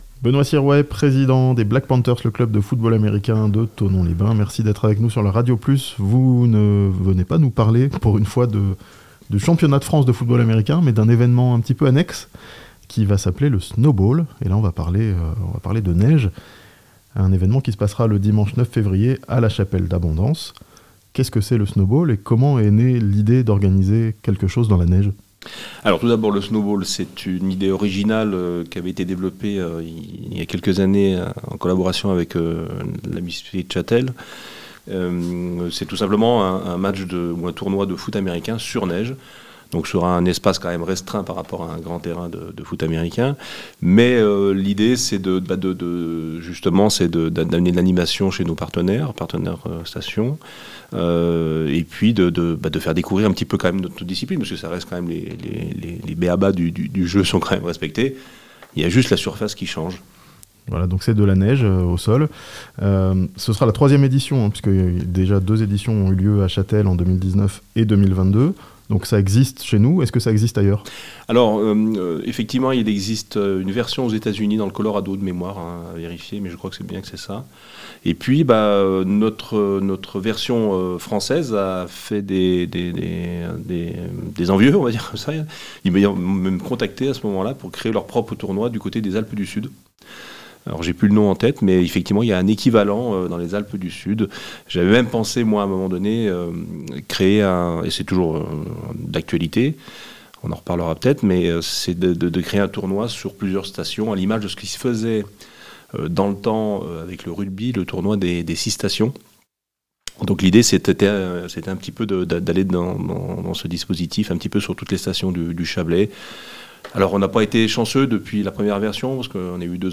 Snowbowl, un match de football américain dans la neige le 9 février à la Chapelle d'Abondance (interview)